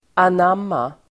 Uttal: [an'am:a]